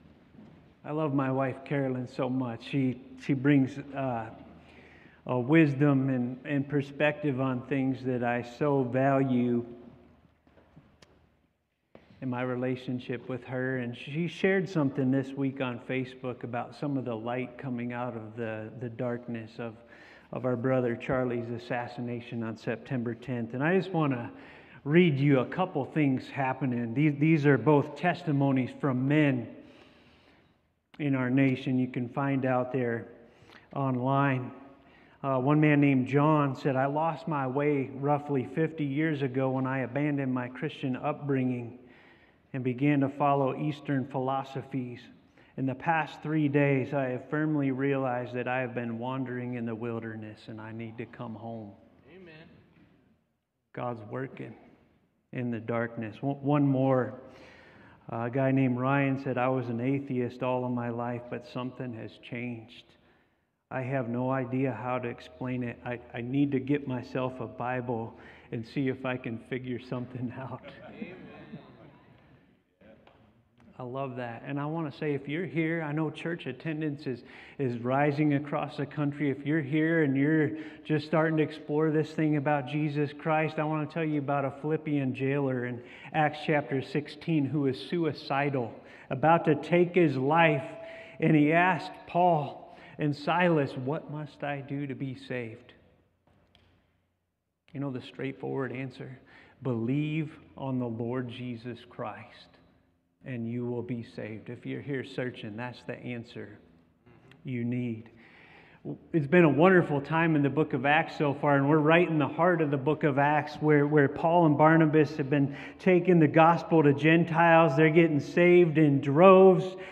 Preparing By Praying (A Special Prayer Service)
9-21-25-preparing-by-praying-a-special-prayer-service.m4a